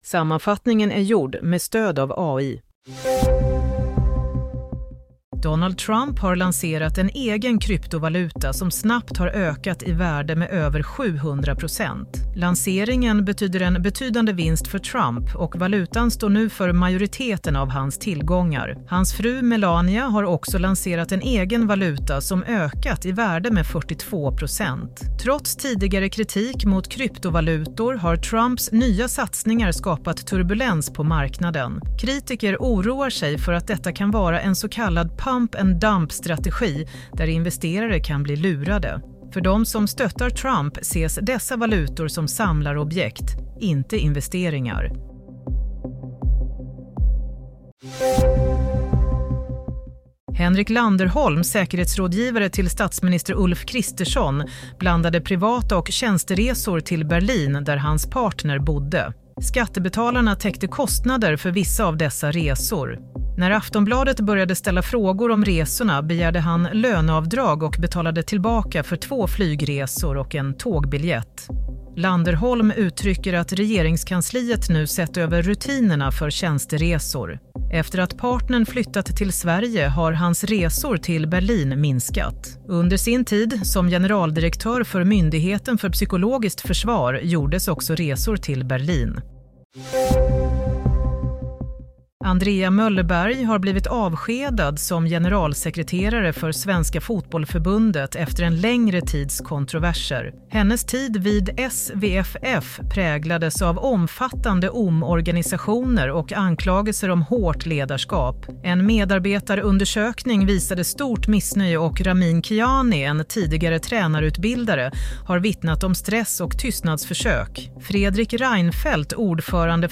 Nyhetssammanfattning - 20 januari 16:00